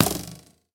bowhit2.ogg